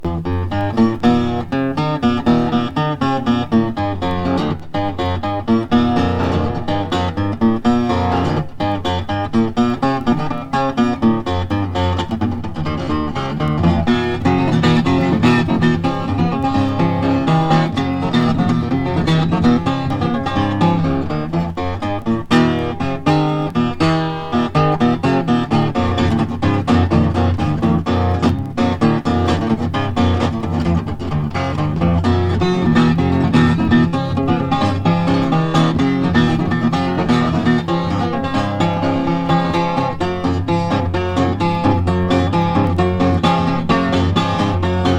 Blues, Folk, World　USA　12inchレコード　33rpm　Mono